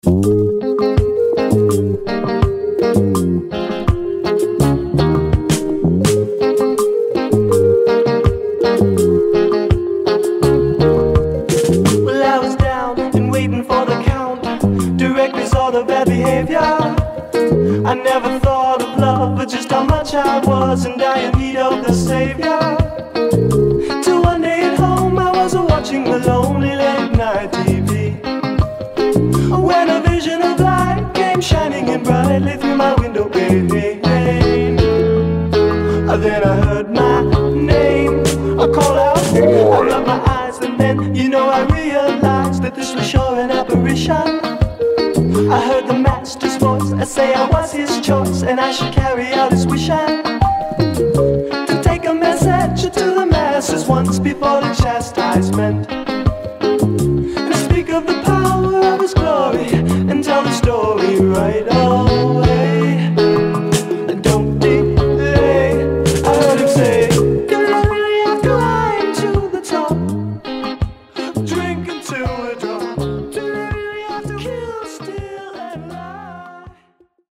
"Pseudo-Reggae"